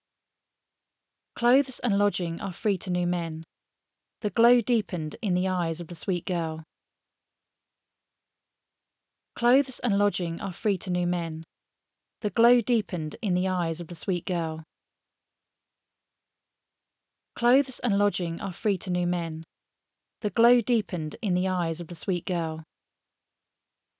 ref_woman_voice_16k.wav